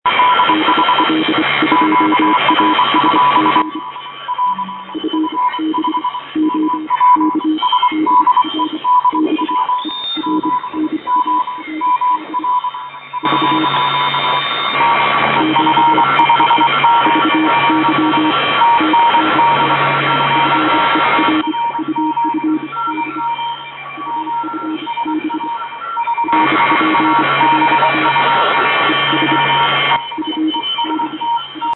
la riduzione del rumore è drastica ed il segnale diventa ben comprensibile.
Tali files sono stati registrati in situazioni reali, sulle bande radioamatoriali.